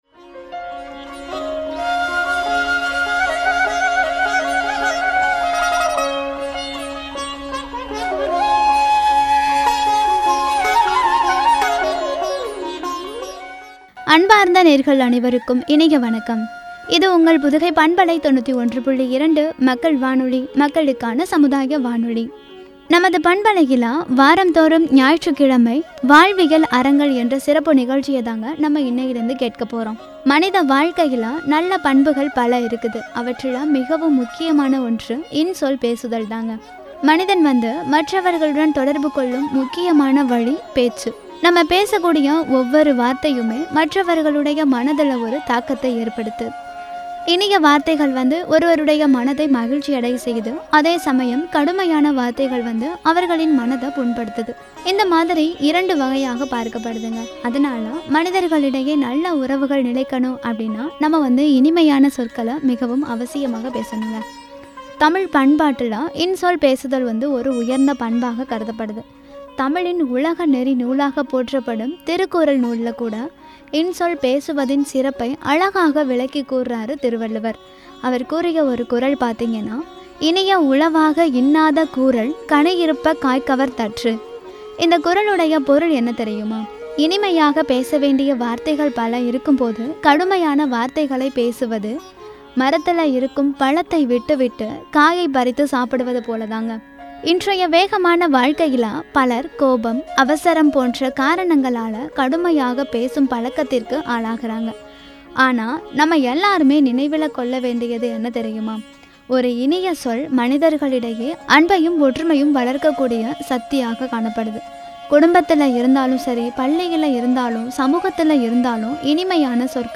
(பகுதி 01)” என்ற தலைப்பில் வழங்கிய உரை.